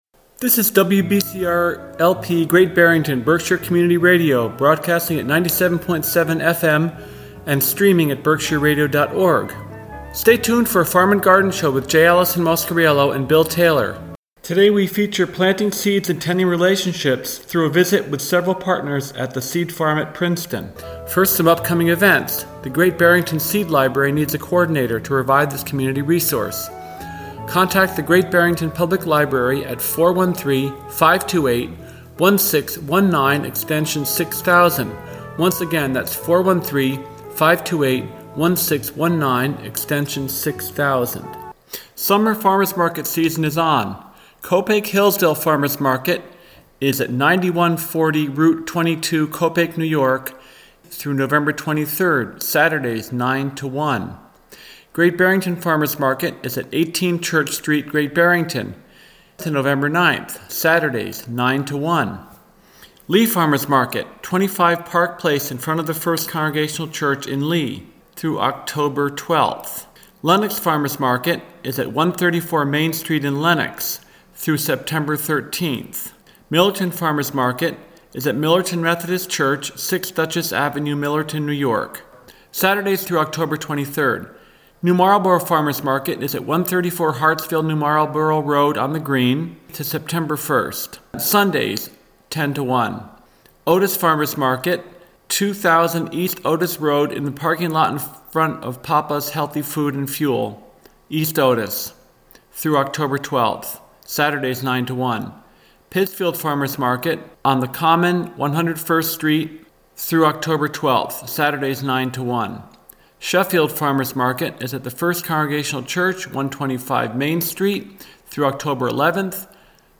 Here are some rough recordings of an Oct 6 concert at Sandisfield Arts Center 4-6 PM, first and second half respectively.
oboe
clarinet and bassoon
French horn
piano
flute and piccolo
voice.
Note that this is from a recorder near the back of the hall.